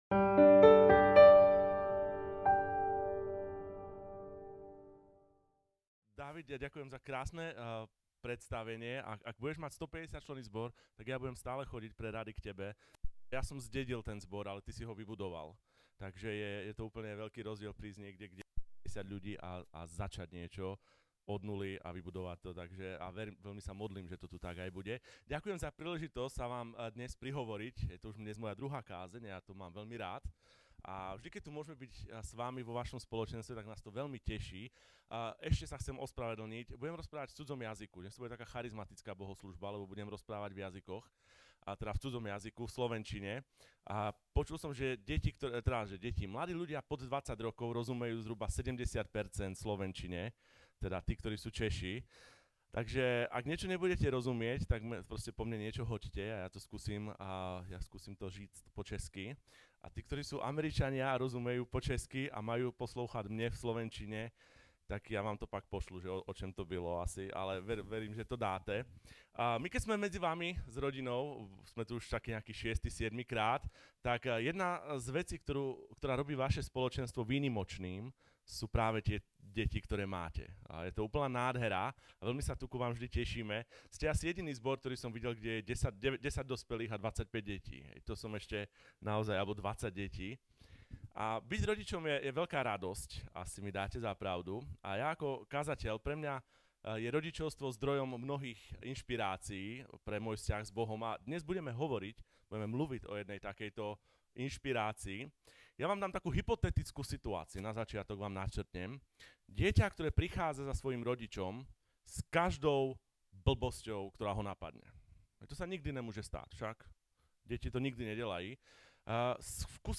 A kázání od série "Velikonoční advent."